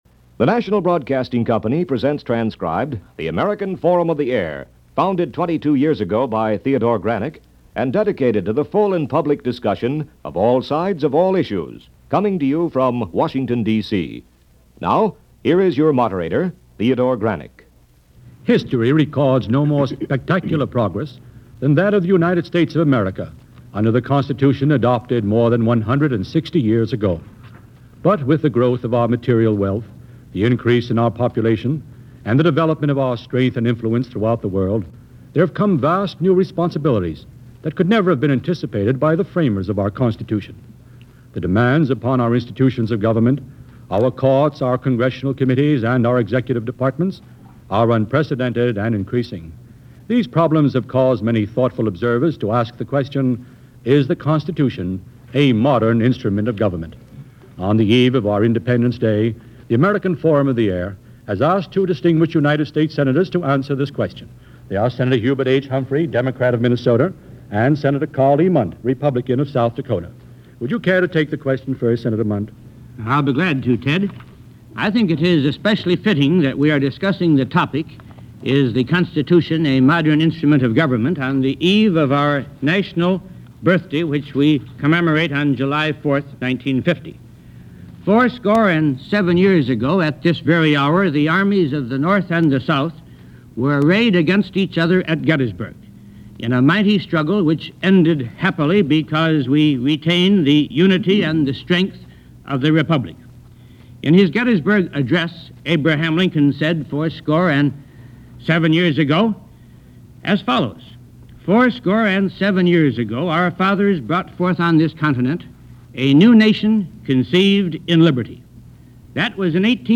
And it was the topic of discussion and debate on this episode of the NBC Radio series American Forum of The Air from July 2, 1950. Was the Constitution simply an outdated instrument? More than that, was Government taking on too much, or not enough? Discussing the question were Senators Karl Mundt (R-SouthDakota) and Hubert Humphrey (D- Minnesota ).